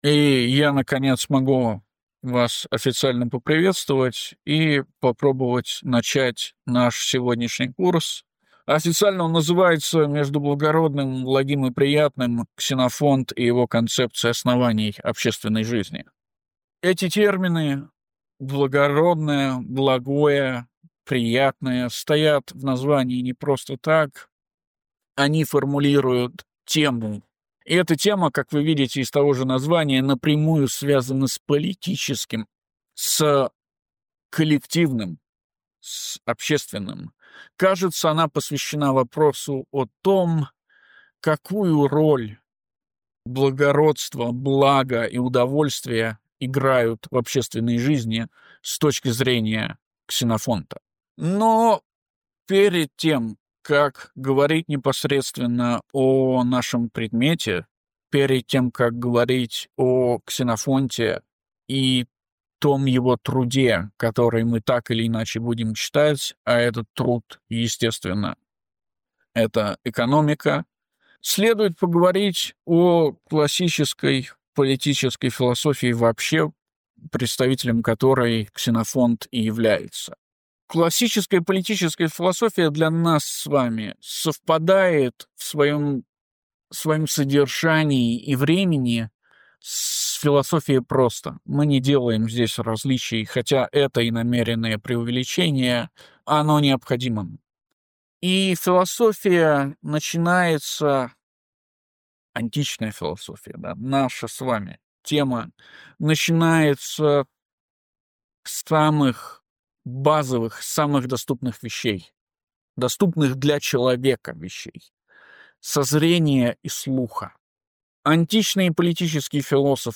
Начинаем публиковать записи магистерского курса по "Домострою" ("Экономике") Ксенофонта.